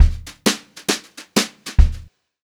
200JZFILL1-R.wav